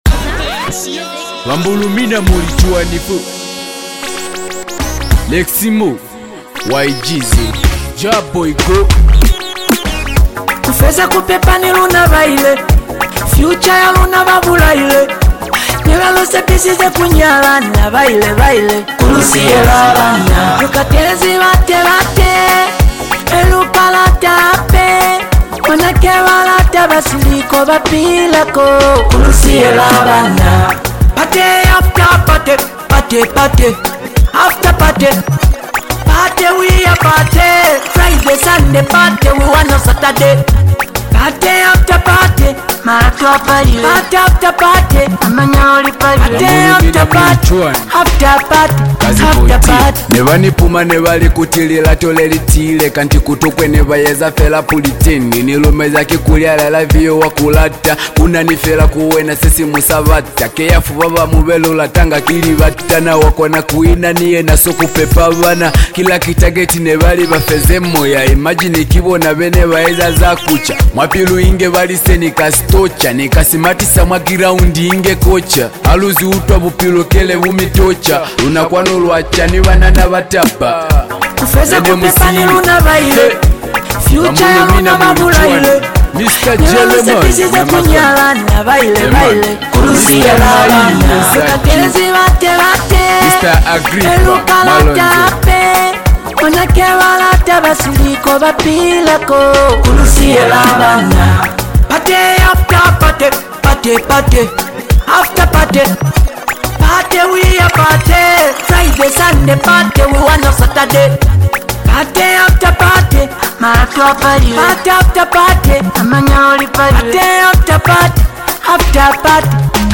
This EP blends emotion, culture, and smooth melodies